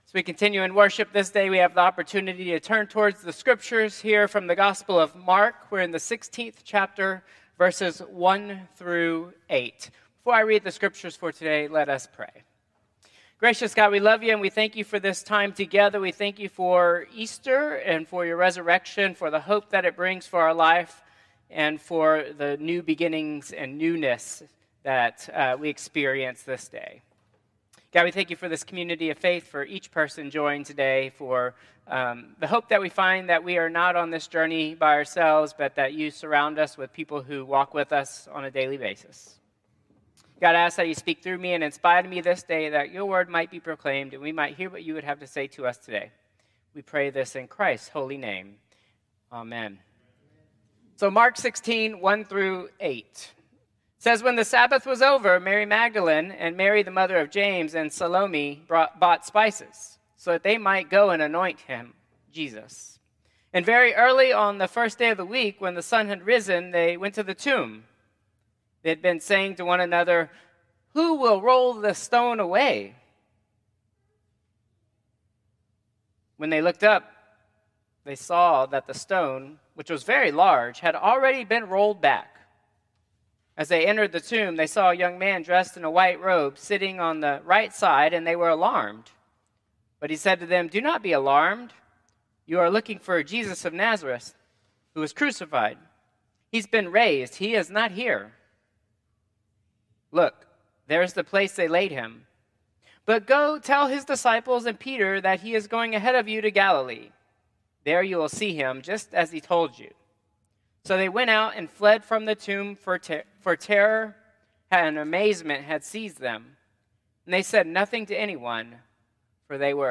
Traditional Easter Worship 4/20/2025